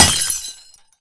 glass_smashable_small_break_01.wav